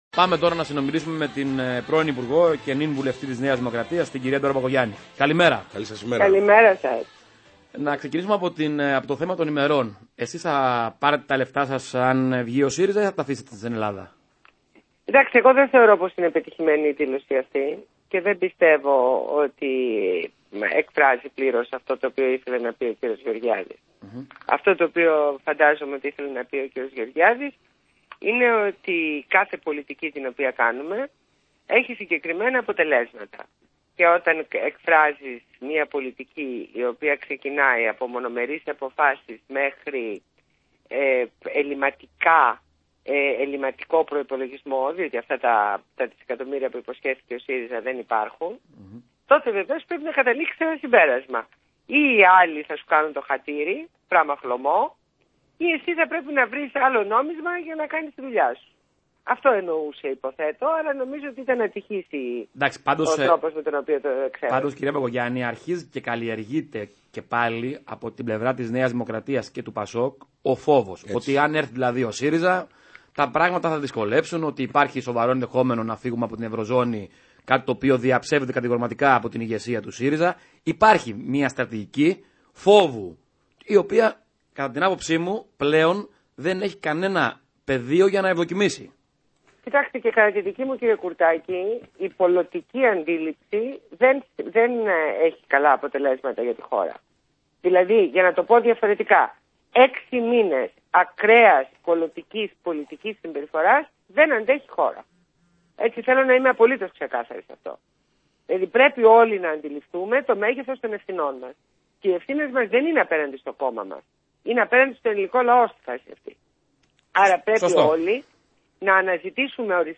Συνέντευξη στο ραδιόφωνο Παραπολιτικά FM 90,1